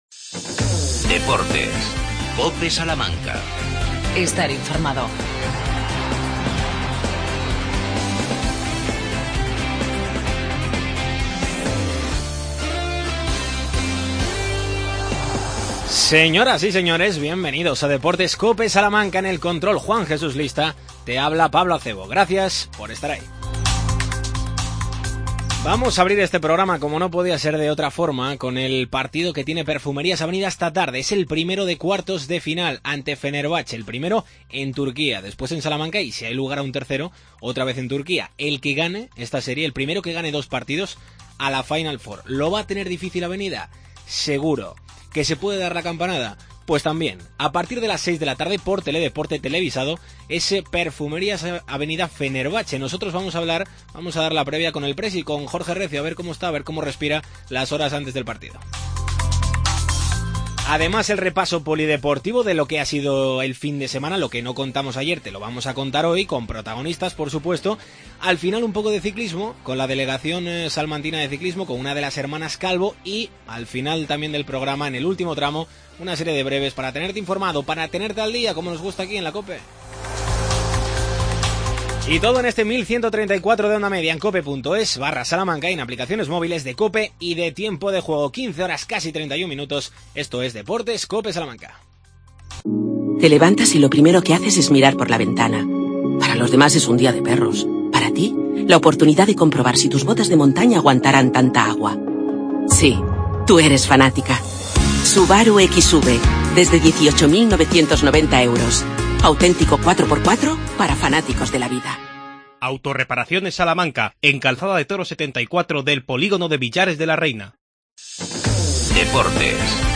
Entrevista con el presidente perfumero